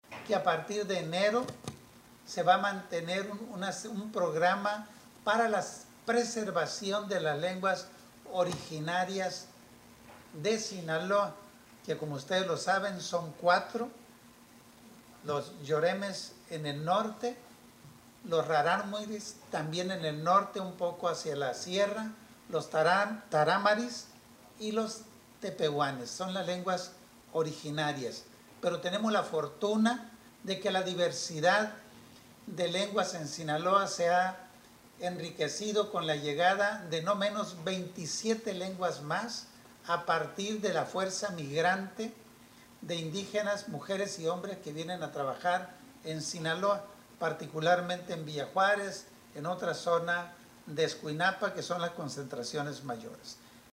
Durante la conferencia de la Vocería Estatal, Castro Meléndrez recordó además que recientemente se dio a conocer una serie de actividades relacionadas a proyectos productivos para los indígenas, sin embargo, lo esencial es otorgarles el derecho a estar bien comunicados desde sus lugares de origen.